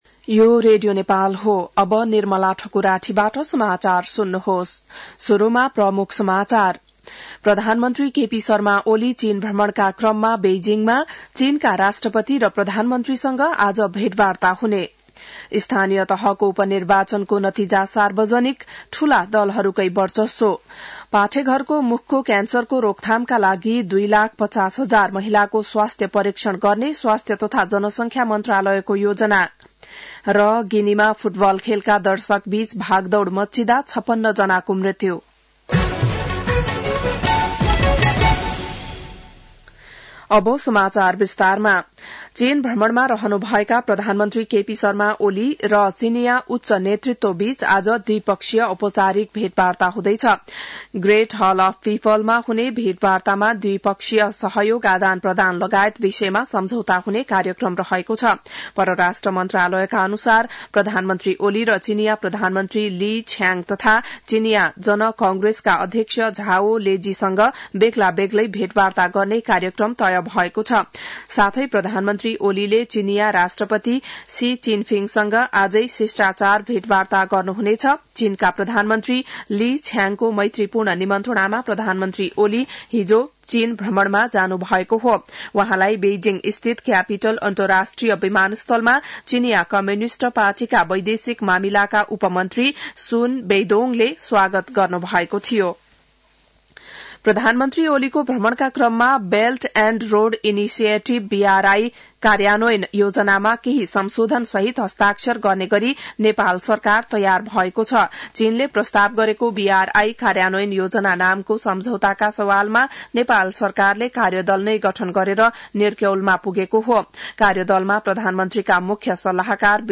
An online outlet of Nepal's national radio broadcaster
बिहान ९ बजेको नेपाली समाचार : १९ मंसिर , २०८१